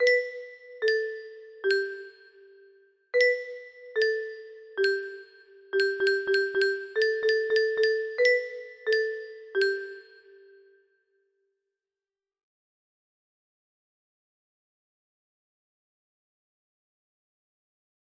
Hot Cross Buns (simple melody) music box melody
It looks like this melody can be played offline on a 30 note paper strip music box!